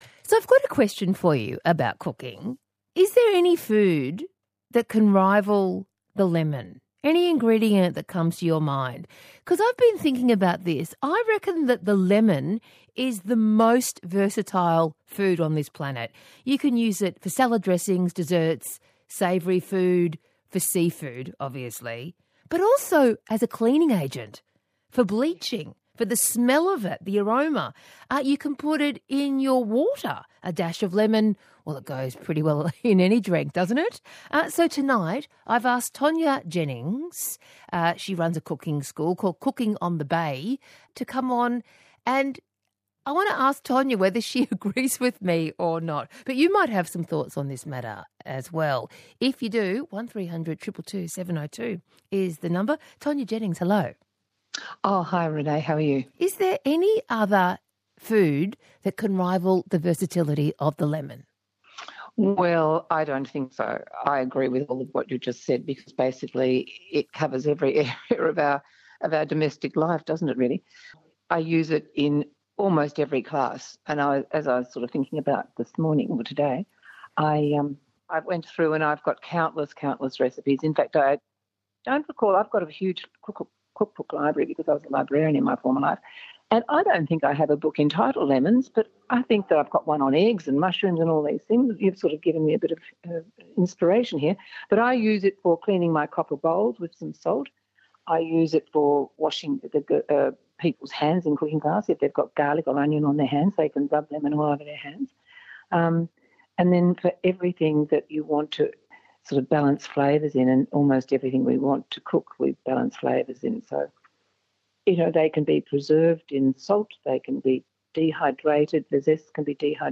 ABC Evenings Radio Interview “Lemons”- February 2025: